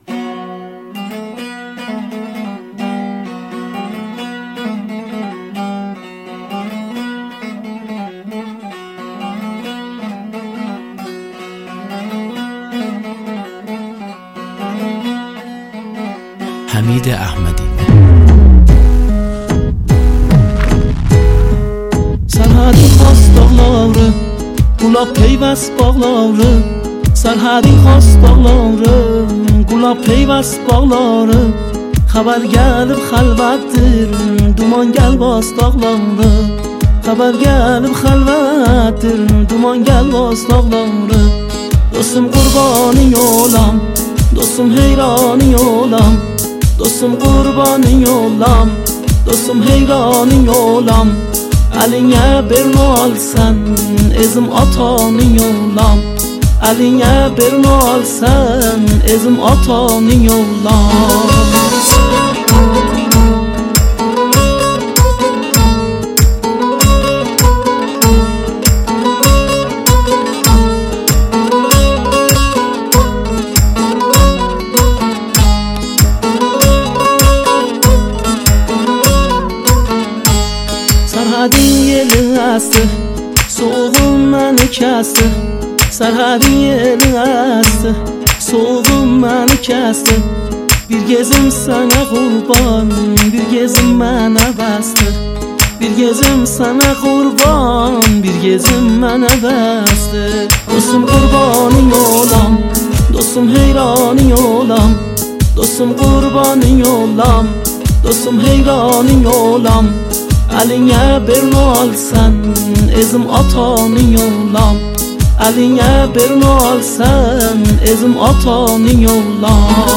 ریمیکس ترکی قشقایی